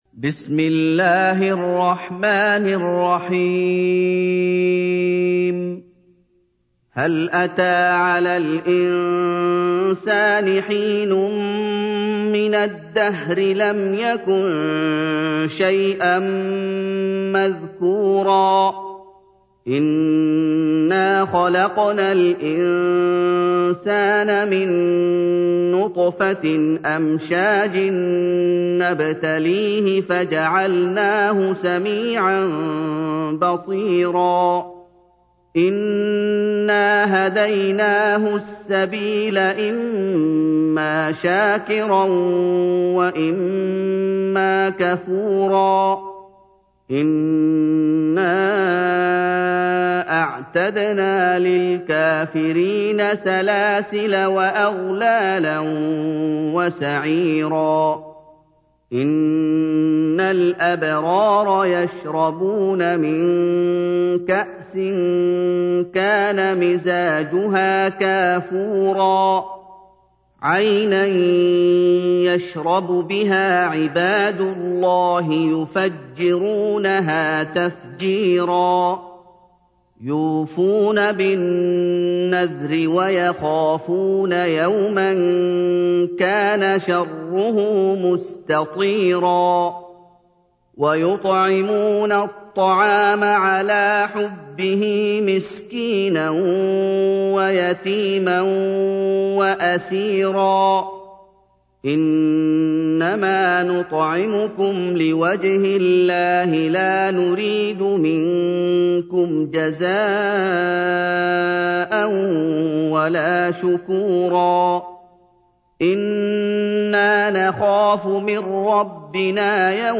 تحميل : 76. سورة الإنسان / القارئ محمد أيوب / القرآن الكريم / موقع يا حسين